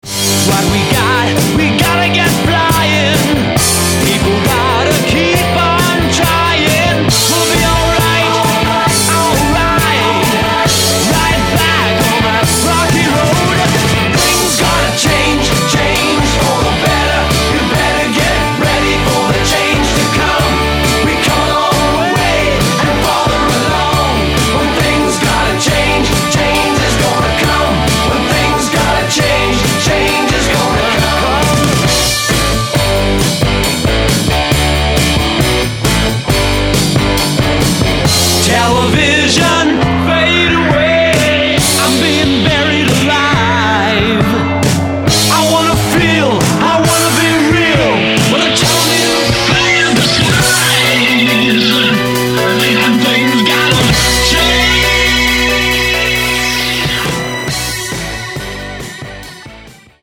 Lead Vocal, Keyboards & Percussion.
Guitars & Vocal.